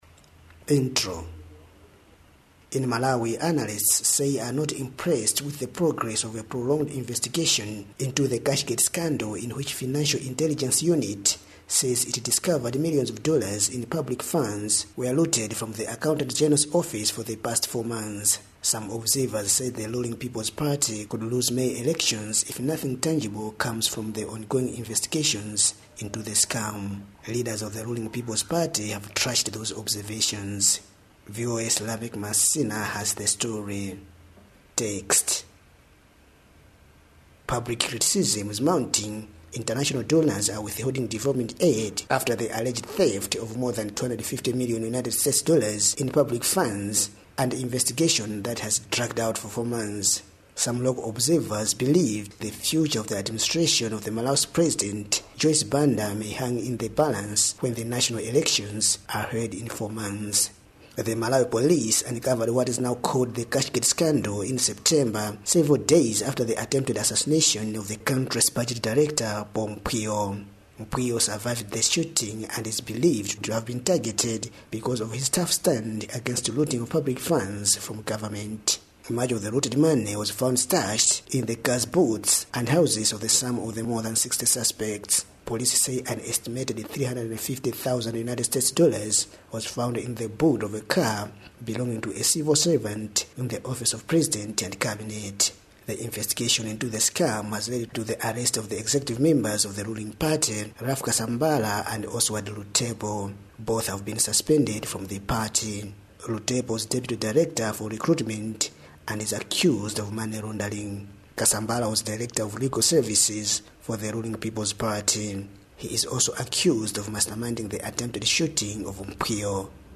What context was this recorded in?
Lilongwe interviews on Cashgate probe